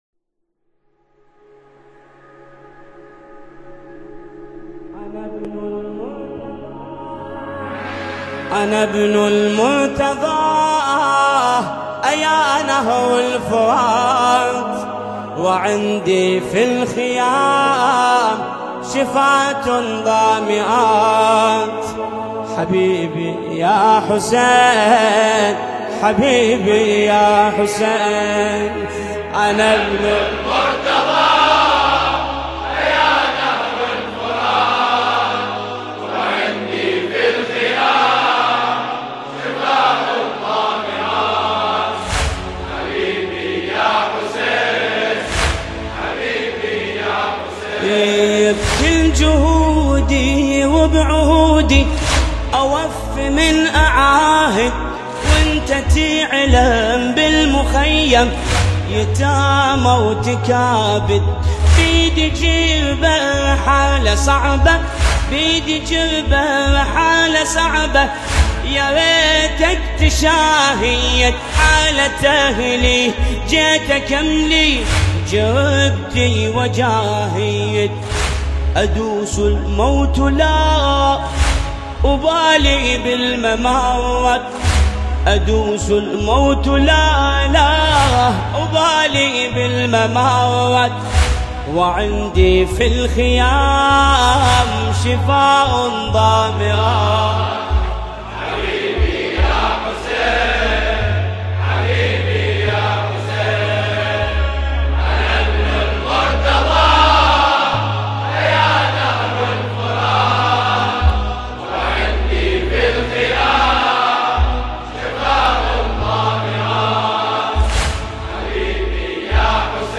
نماهنگ دلنشین عربی